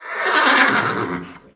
c_whorse_atk3.wav